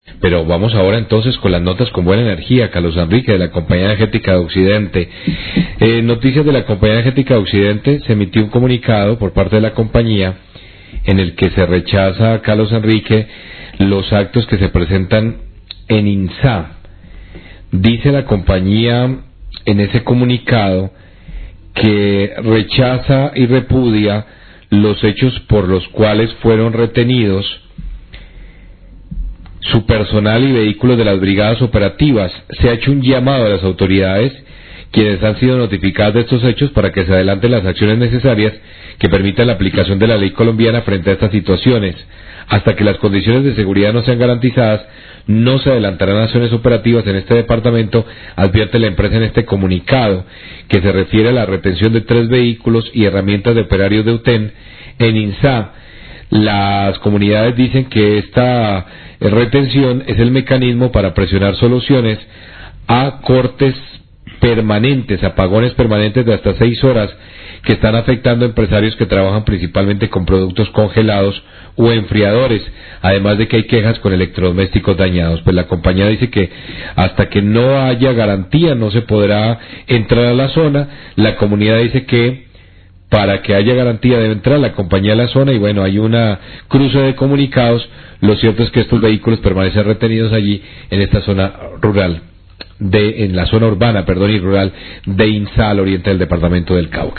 Radio
comunicado de prensa